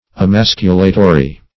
Emasculatory \E*mas"cu*la*to*ry\
emasculatory.mp3